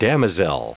Prononciation du mot damozel en anglais (fichier audio)
damozel.wav